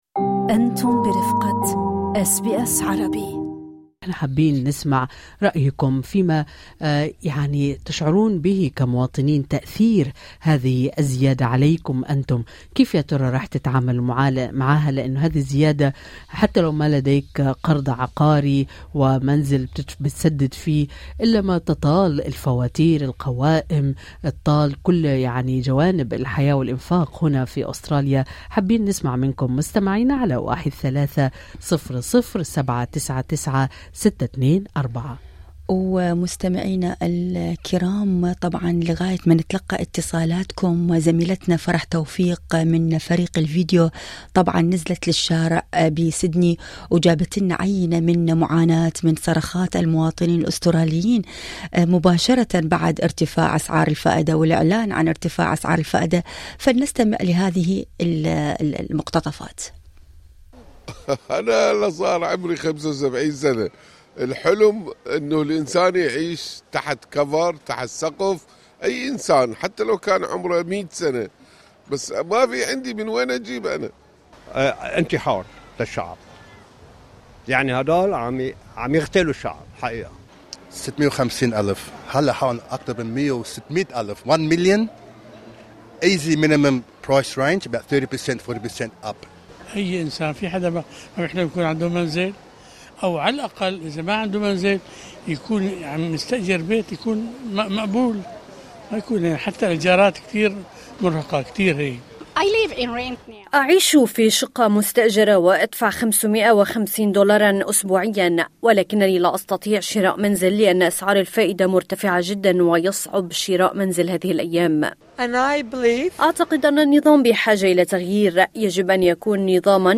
تفاعل عدد كبير من مستمعي برنامج أستراليا اليوم مع قرار مصرف الاحتياط الأسترالي الأخير برفع سعر الفائدة إلى 3.85%، وأجمع كثير منهم على أن تأثيرات هذا القرار لن تقتصر على من لديه قرض عقاري فقط، بل ستطال جوانب الحياة اليومية كافة، من الإيجارات وصولاًًُ إلى فاتورة المعيشة.